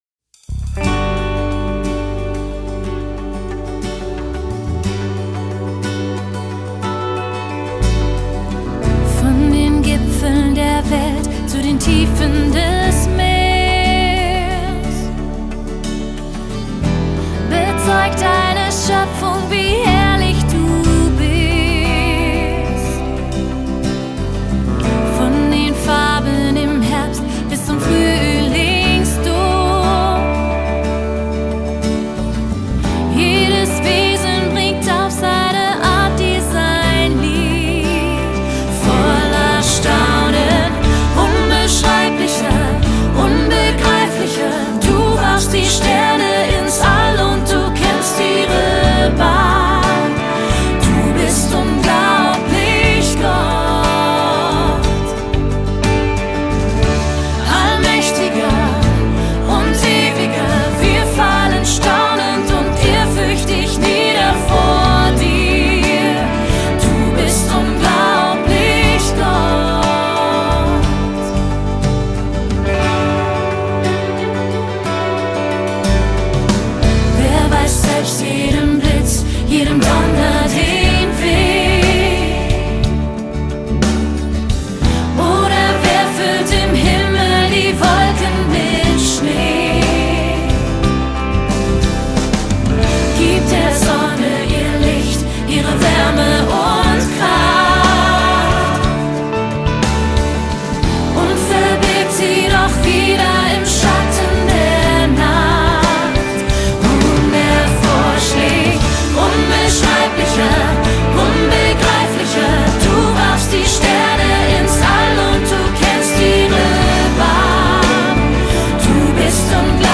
45 просмотров 54 прослушивания 0 скачиваний BPM: 90